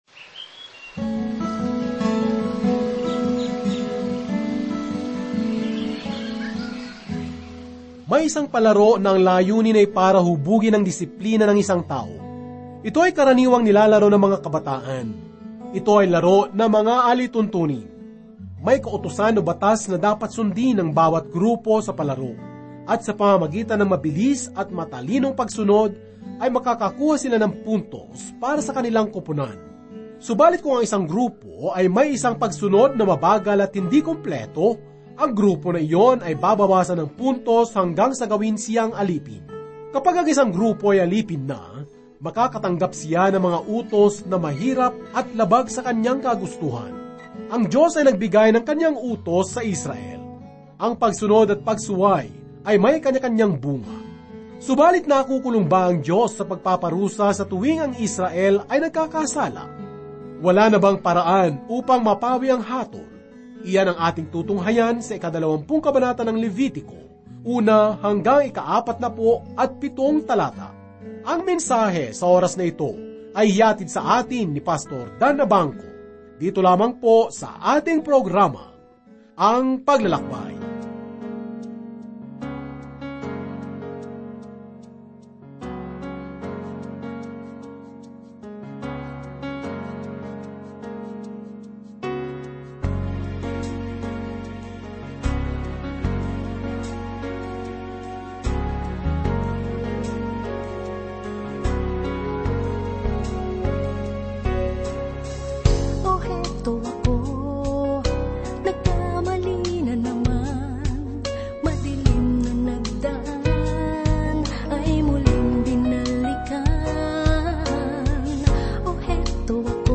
Ito po'y 30 minutong programa sa radio na naglalayon na sistematikong akayain ang mga tagapakinig sa kabuuan ng Salita ng Diyos.